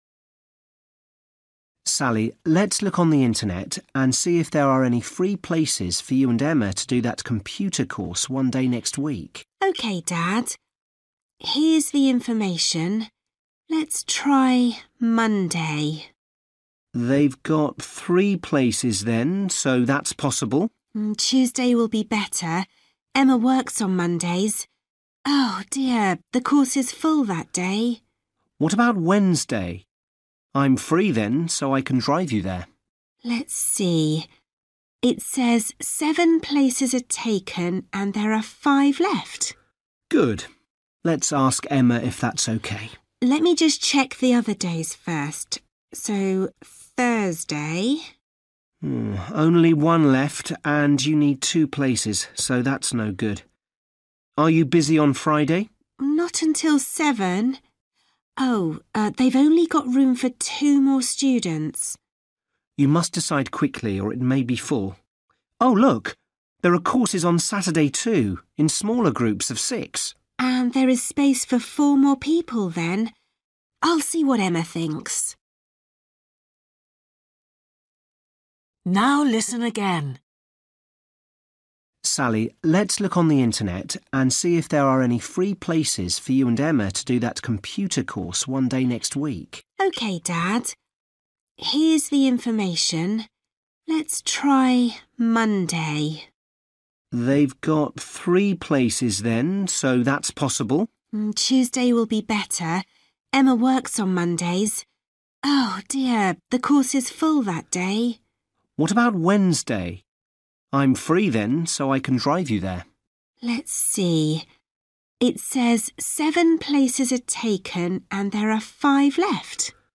Listening: How many free places are there on the computer course each day?